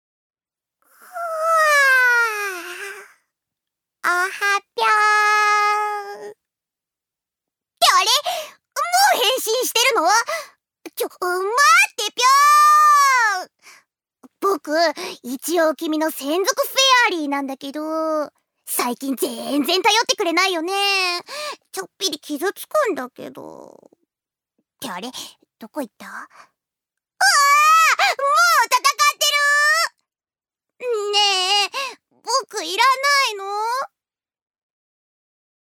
ジュニア：女性
セリフ４